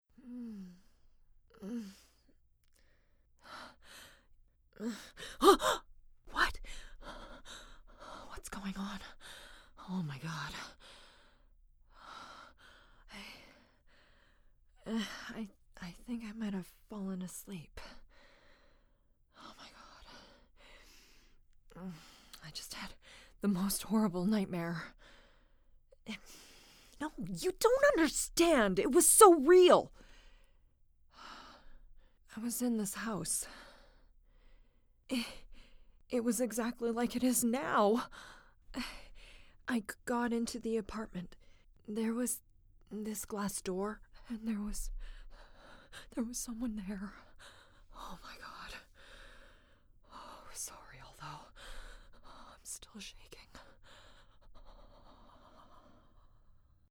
Female
Yng Adult (18-29), Adult (30-50)
Natural, conversational, feminine, bright optimism inside a warm, nurturing, caring voice served up with a side of dry wit and wry sarcasm. Accents & Characters: Standard American, Canadian, Quirky, Girl Next Door, Mom.
Video Games
0531Videogame_Demo.mp3